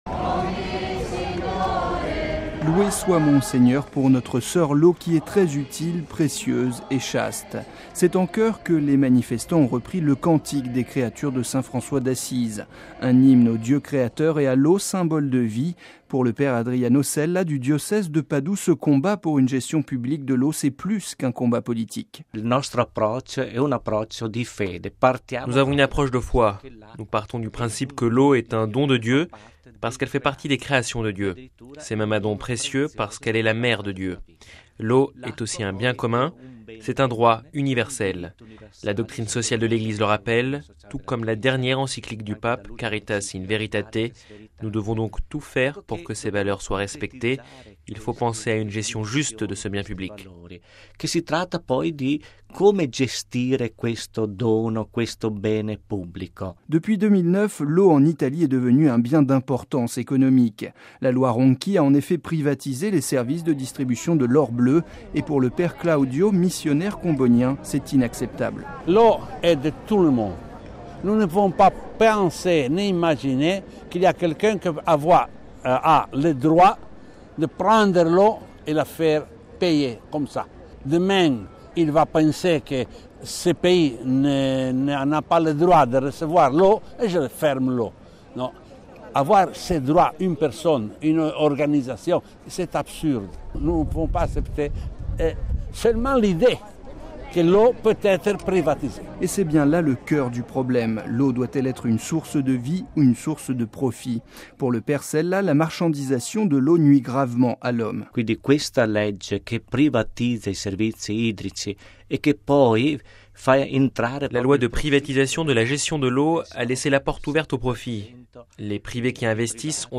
Les Italiens sont en effet appelés à se prononcer sur la privatisation de la gestion de l’eau dans le pays. Les manifestants ont appelé à sauvegarder cette ressource commune, une manifestation ponctuées de prières et de chants.
Le reportage